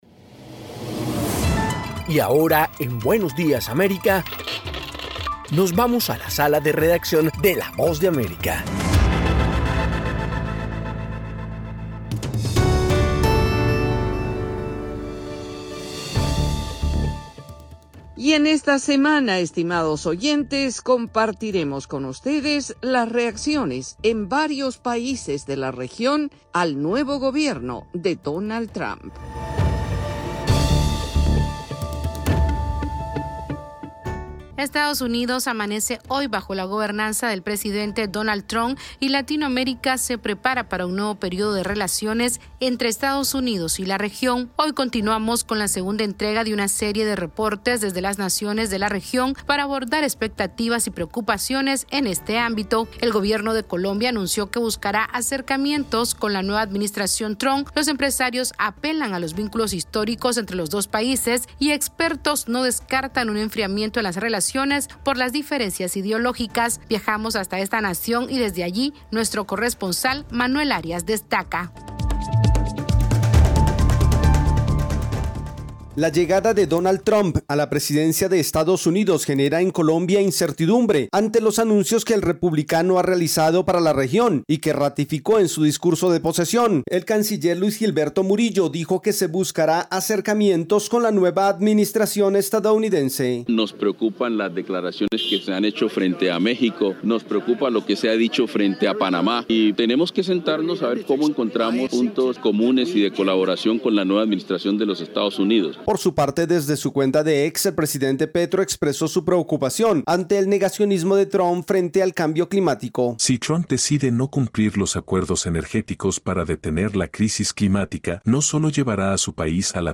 AudioNoticias
Continuamos la difusión de una serie de reportes desde la región para analizar expectativas y preocupaciones ante el regreso de Donald Trump a la Casa Blanca.